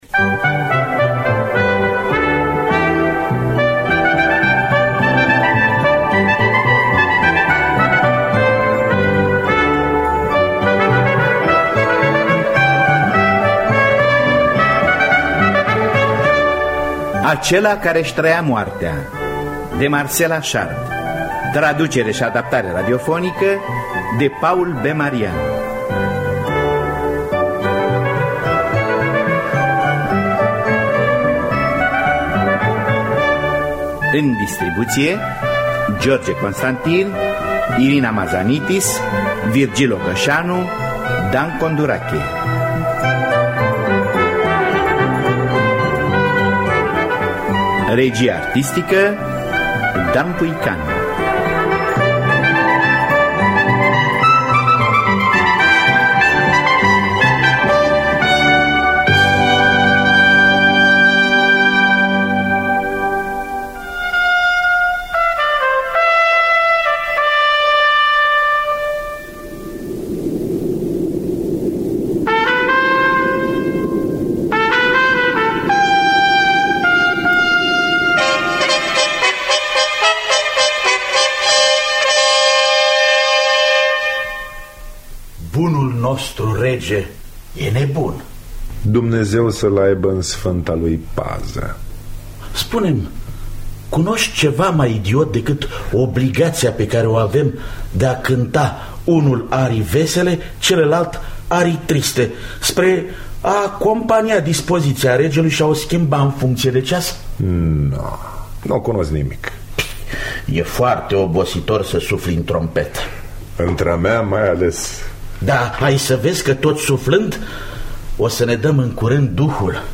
Marcel Achard – Acela Care Isi Traia Moartea (1992) – Teatru Radiofonic Online